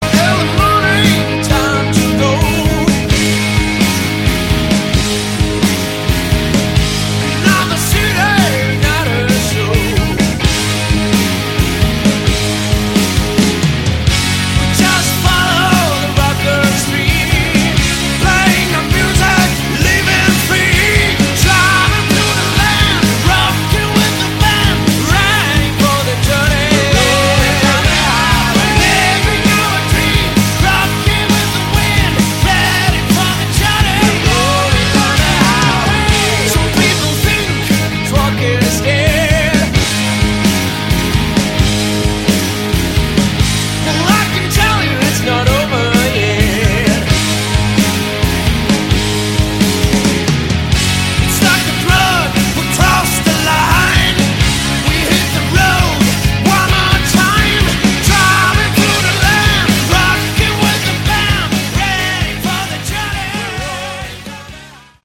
Category: Southern Rock
guitar, vocals
bass
drums